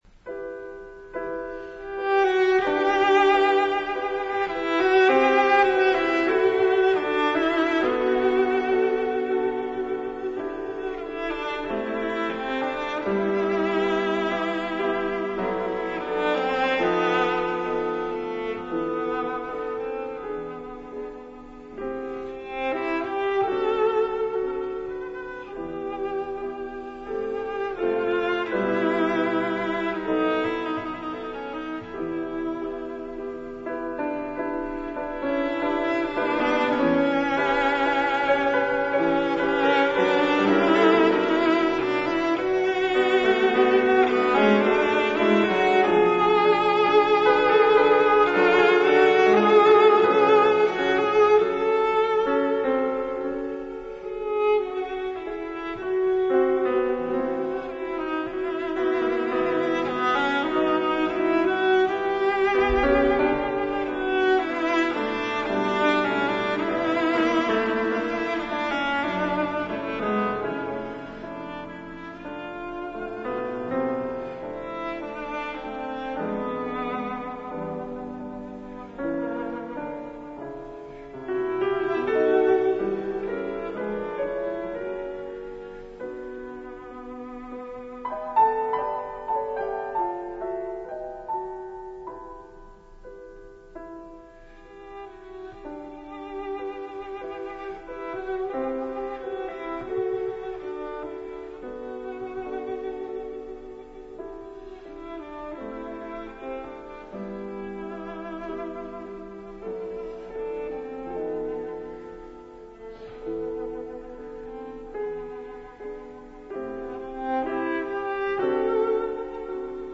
У другом делу емисије, од два до четири часa ујутро, слушаћемо делове радио-драме Ex Ponto, рађене по истоименом делу Иве Андрића.